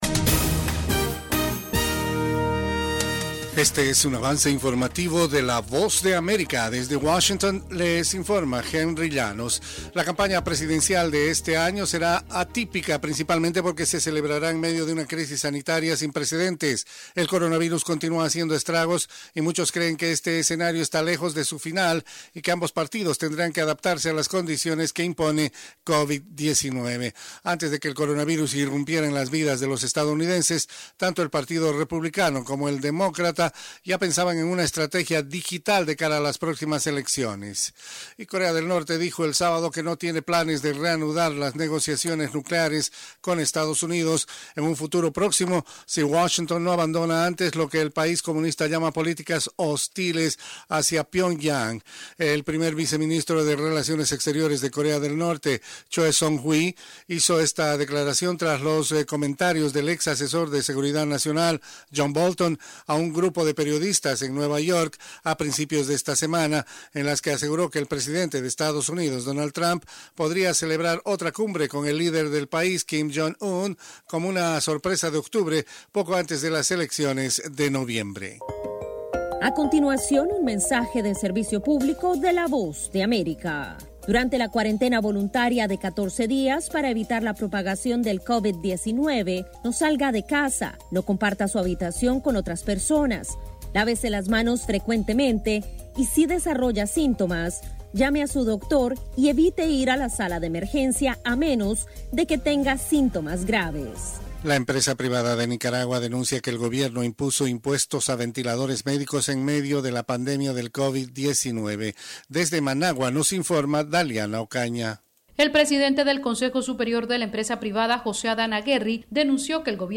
Cápsula informativa de tres minutos con el acontecer noticioson de Estados Unidos y el mundo.